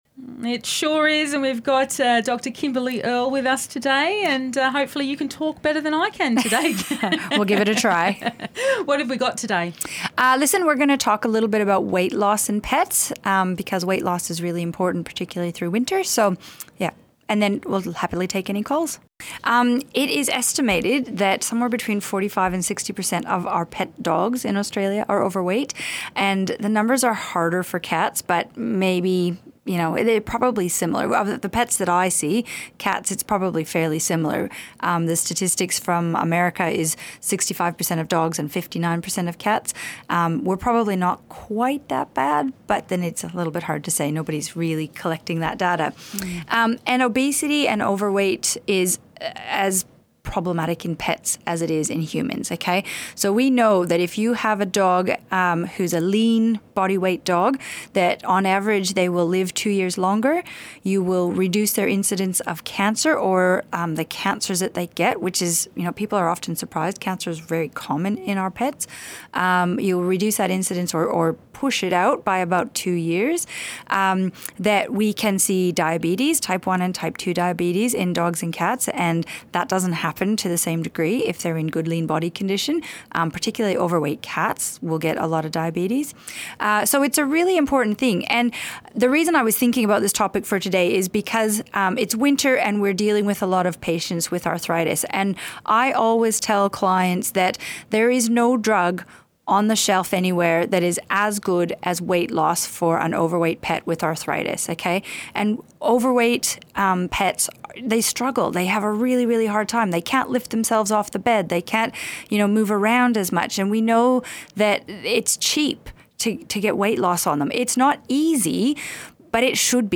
Animal behaviour specialists and vets talk about pets and answer questions from listeners.
A weekly phone-in show about pets in Australia.